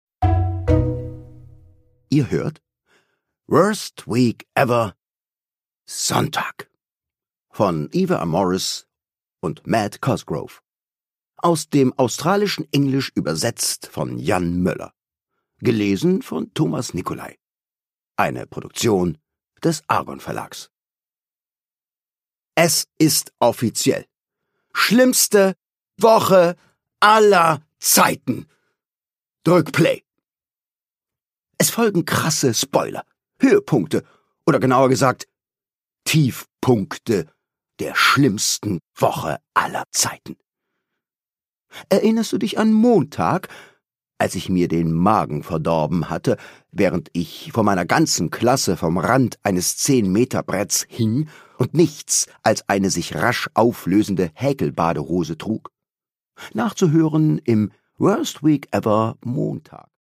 Produkttyp: Hörbuch-Download
Gelesen von: Thomas Nicolai